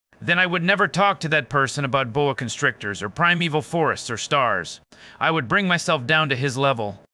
Zero-shot TTSVoice CloningEmotion ControlSpeech Synthesis
A state-of-the-art zero-shot text-to-speech model that generates expressive speech by cloning voices from reference audio while allowing emotional intensity and speech pattern adjustments.
"exaggeration": 0.5,